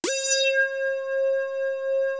JD SYNTHA2.wav